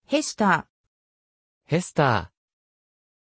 Pronunția numelui Hester
pronunciation-hester-ja.mp3